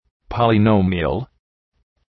Προφορά
{,pɒlı’nəʋmıəl}